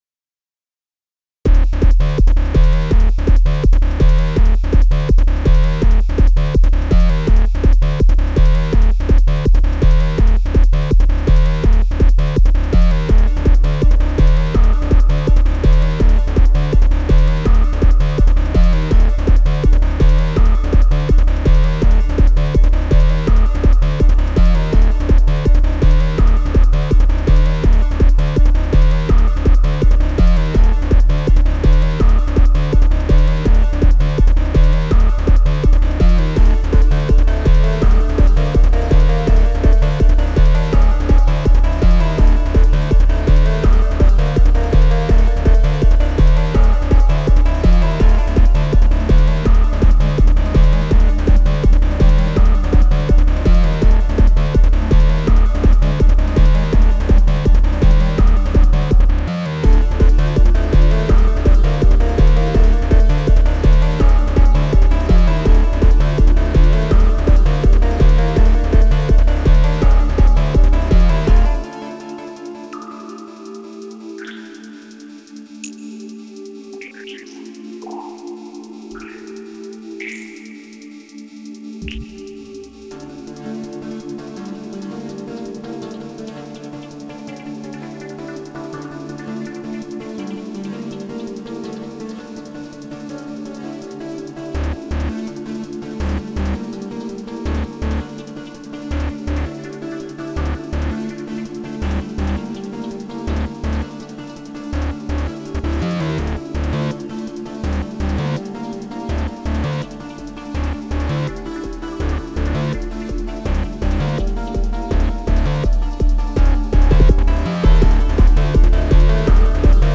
My wife describes this piece as “dystopian”.